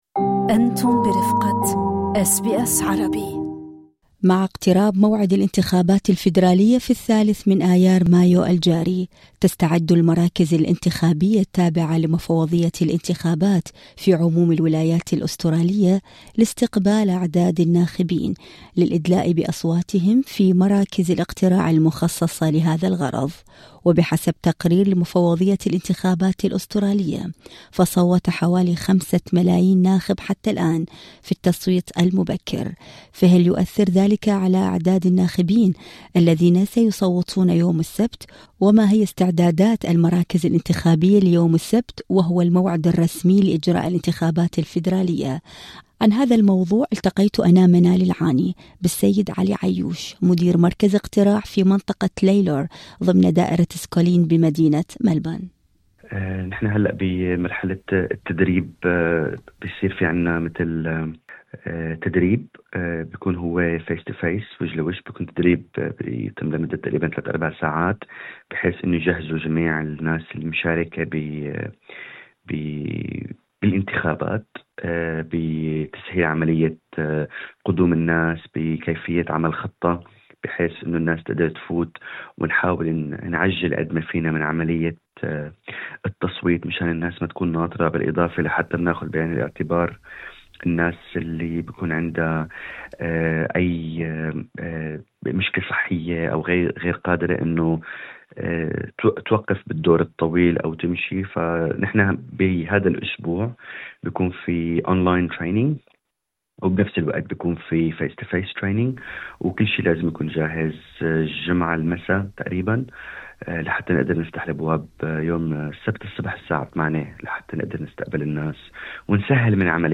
أجرينا لقاءً